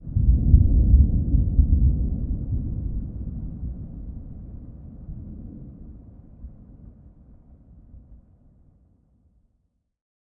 add thunder sounds
thunder_0.ogg